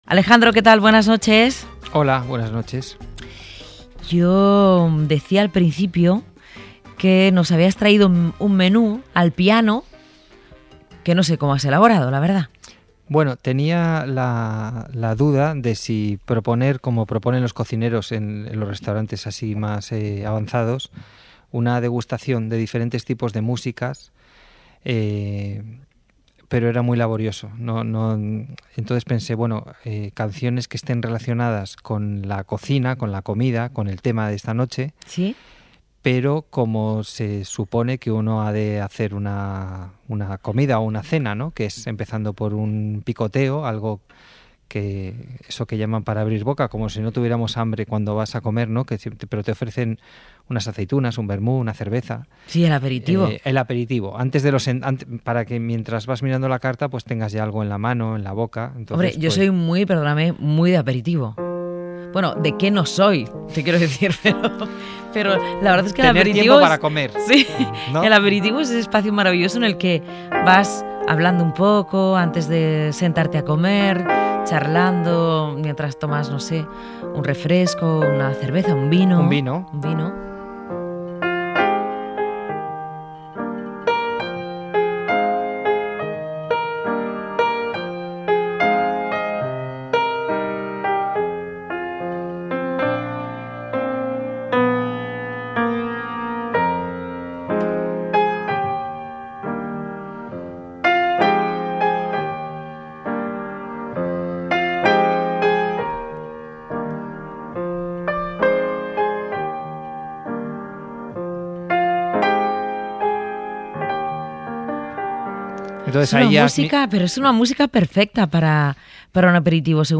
al piano